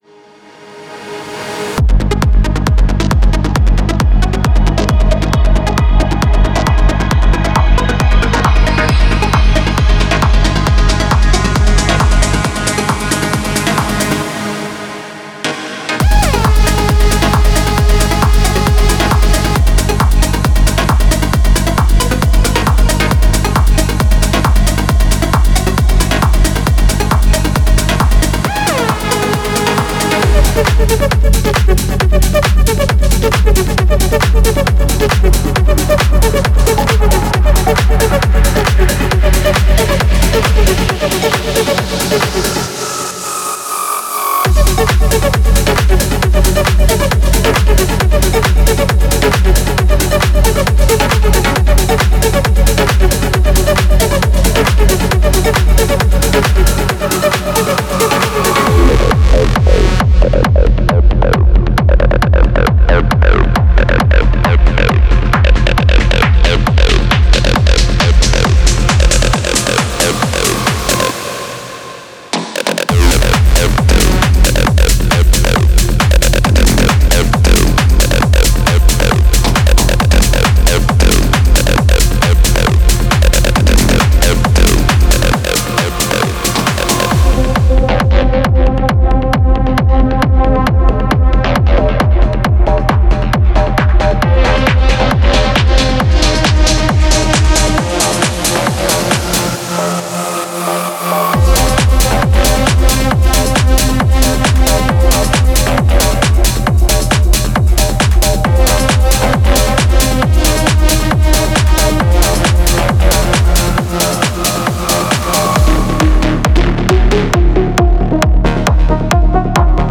Type: Midi Samples
Future House Future Rave Tech House Techno